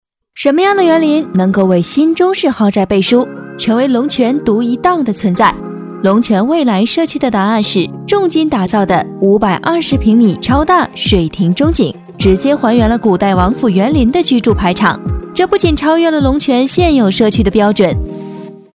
女277-自媒体-探盘
女277角色广告专题 v277
女277-自媒体-探盘.mp3